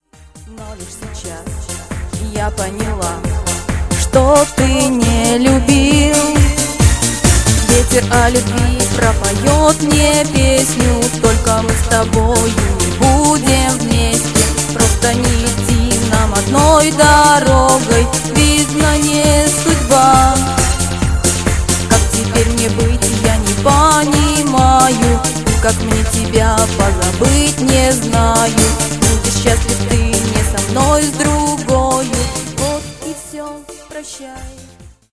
• Жанр: Танцевальная